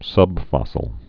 (sŭbfŏsəl)